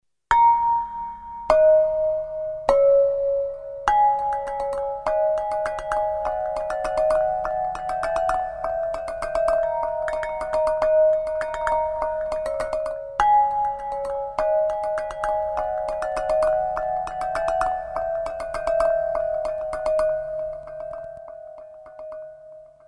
Digital delay with pitchshifting engine plus special tridimensional acoustic Roland RSS system.
bowl ambient
bowl-multi.mp3